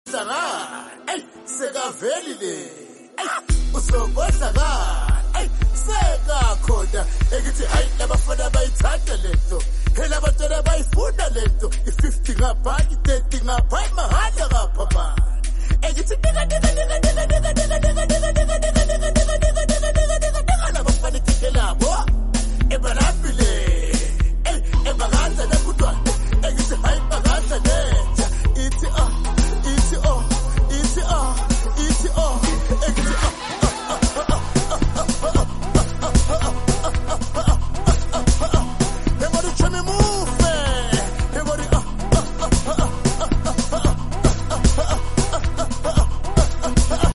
which is a perfect blend of Afrobeat music